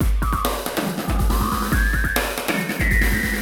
E Kit 35.wav